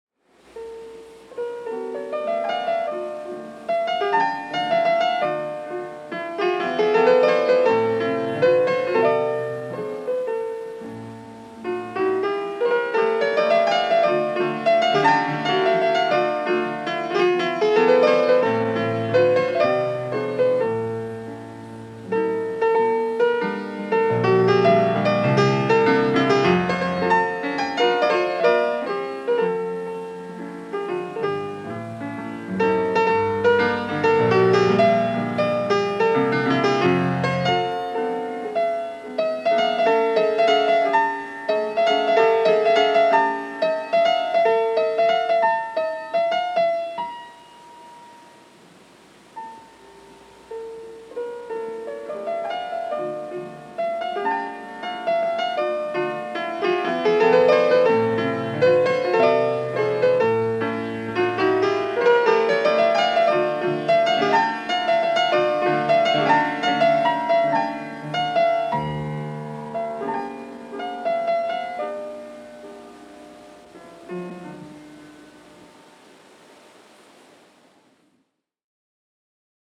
en vivo, año 1982
piano
en la bemol mayo